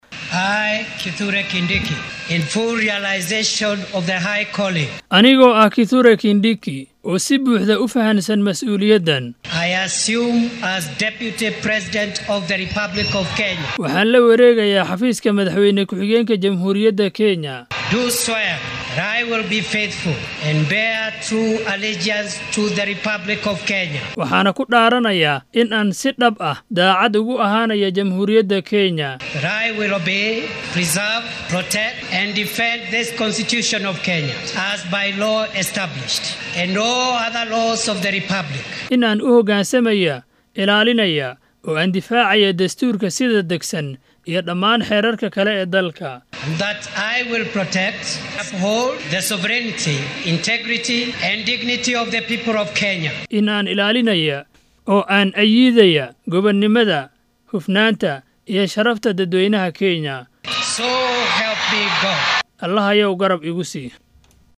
Madaxa diiwaangelinta ee waaxda garsoorka Winfridah Boyani Mokaya ayaa Kindiki ku dhaarisay xarunta shirarka caalamiga ee KICC ee magaalada Nairobi. Kithure Kindiki ayaa wax yar un ka dib abaare 11-kii barqonnimo ku dhaartay inuu si daacad ah u gudan doono waajibaadkiisa shaqo ee madaxweyne ku xigeenka dalka.